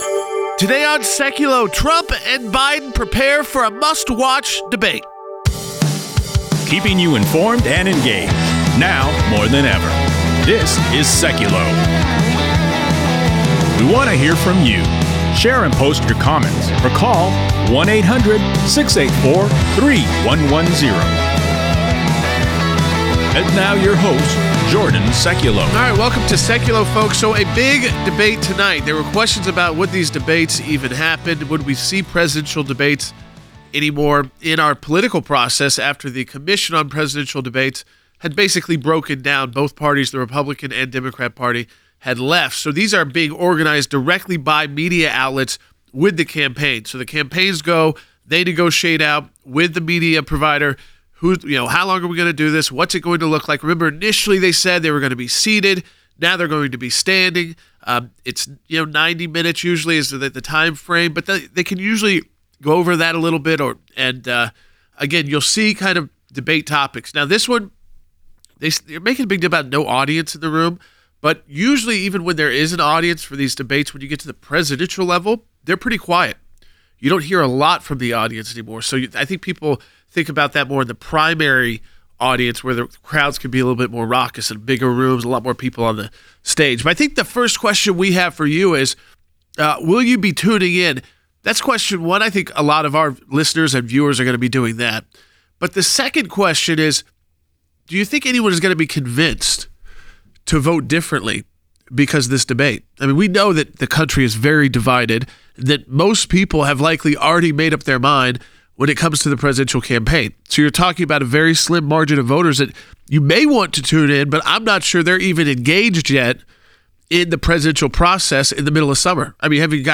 LIVE: Trump and Biden Prepare For Debate